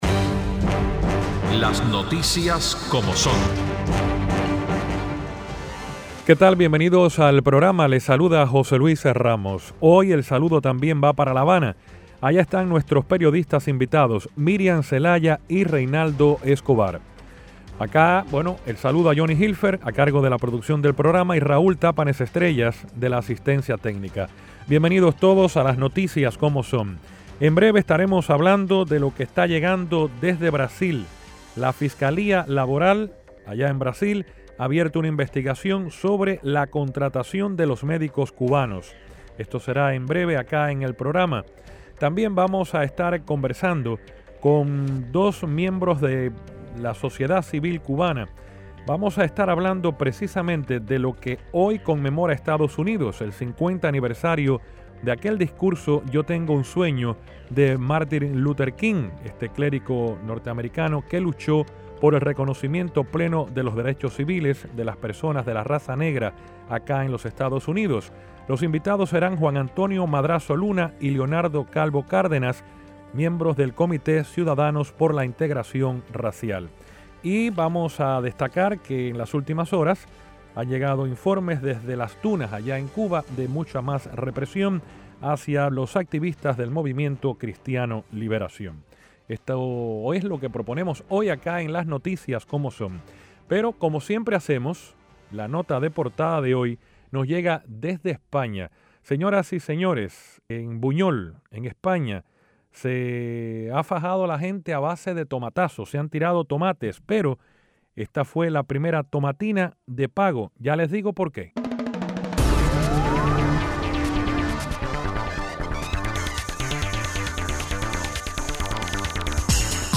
Los periodistas
ambos desde La Habana